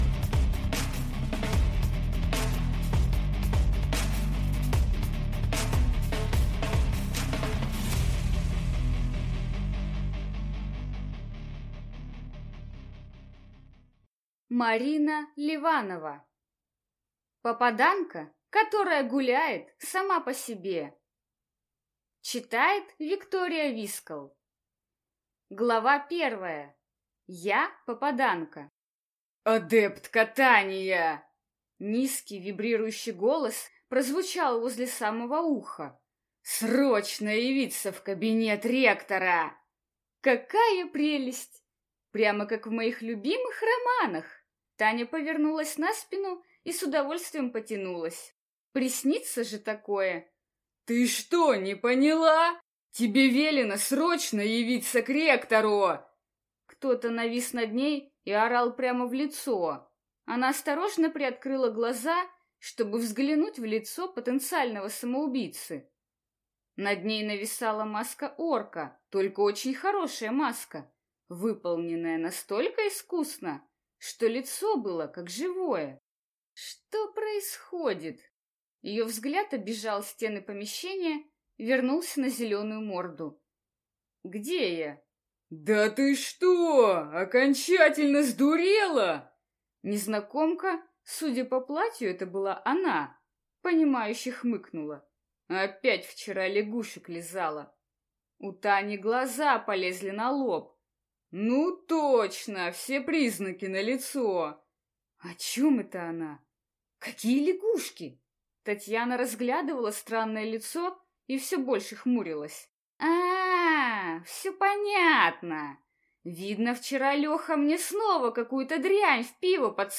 Аудиокнига Попаданка, которая гуляет сама по себе | Библиотека аудиокниг